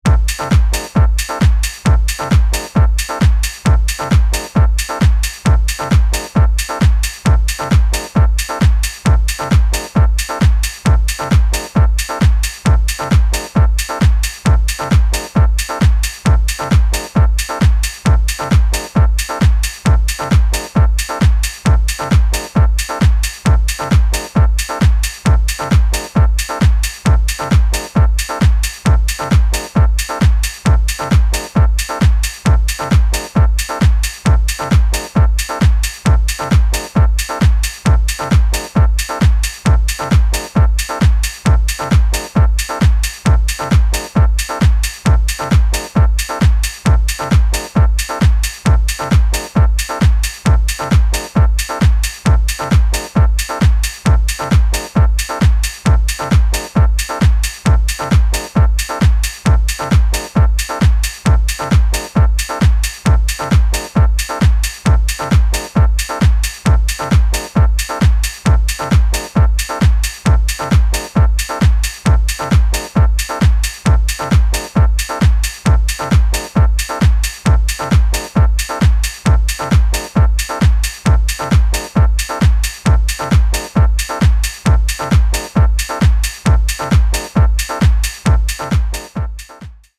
a raw yet simple and minimalist approach to house music